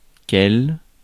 Ääntäminen
Ääntäminen France (Paris): IPA: /kɛl/ Haettu sana löytyi näillä lähdekielillä: ranska Käännöksiä ei löytynyt valitulle kohdekielelle.